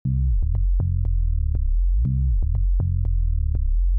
Paso 4: Secuenciación del pitch estilo TB303
Dibuja en una curva de tono, para que tengamos una buena modulación de tono estilo ‘swoopy’.
Modulación de pitch